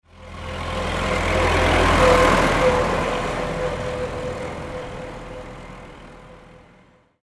Tractor Passing Sound Effect
Realistic sound effect of a tractor driving on an asphalt road from left to right. Perfect for films, games, and videos that need authentic rural or farm ambience.
Tractor-passing-sound-effect.mp3